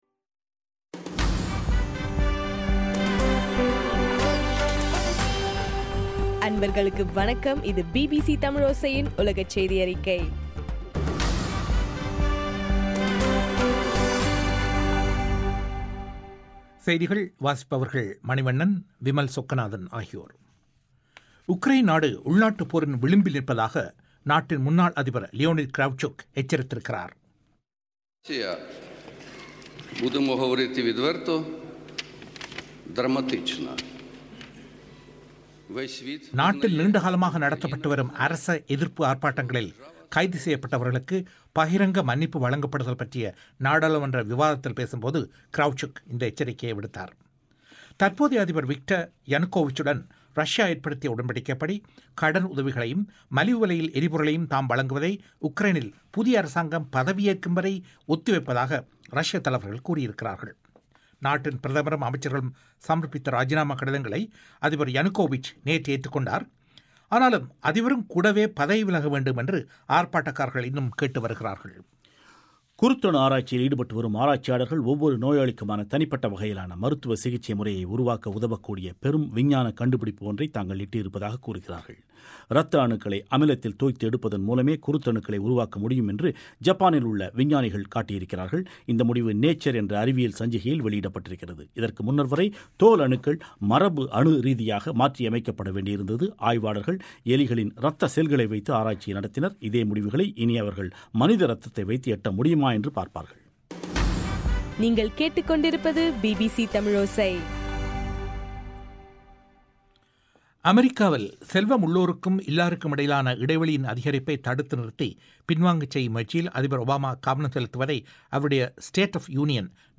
ஜனவரி 29, 2014 பிபிசி தமிழோசையின் உலகச்செய்திகள்